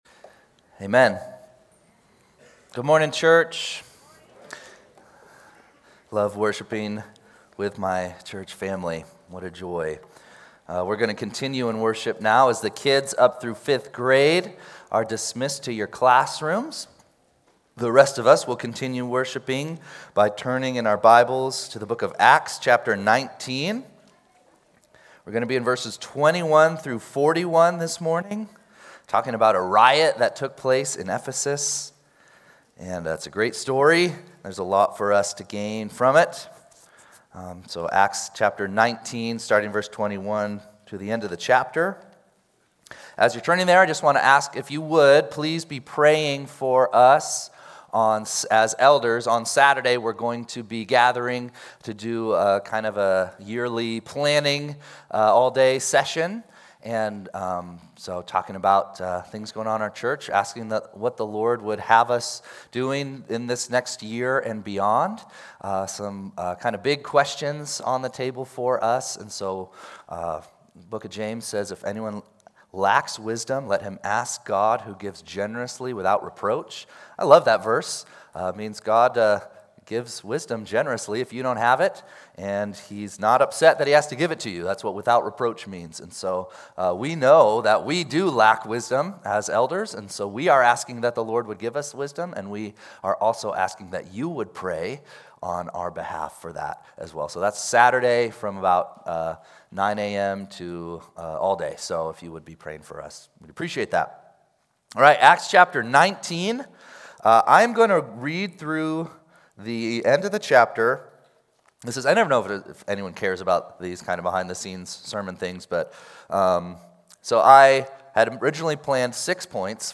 3-1-26-Sunday-Service.mp3